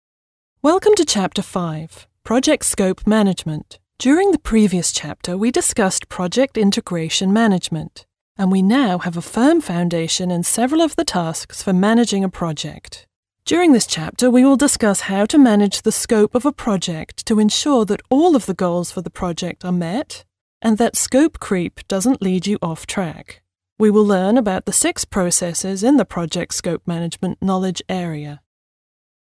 Englischer (britischer) Synchronsprecher